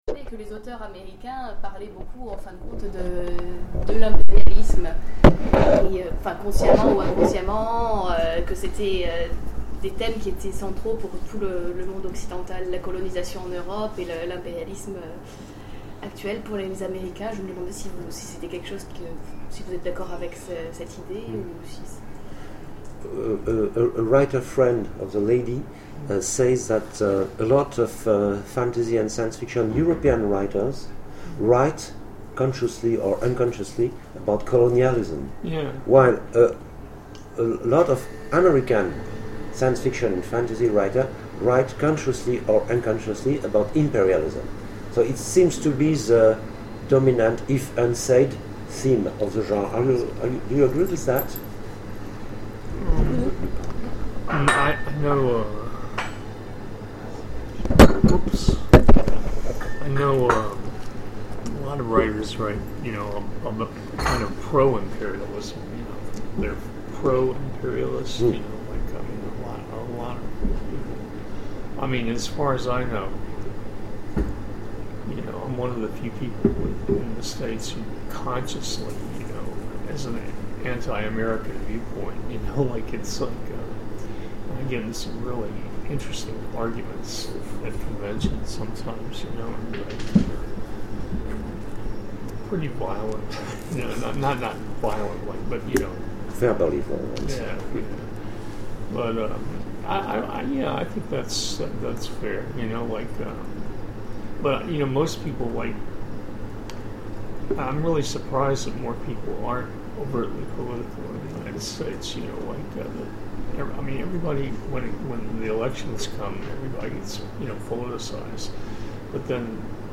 Imaginales 2013 : Petit déjeuner avec Lucius Shepard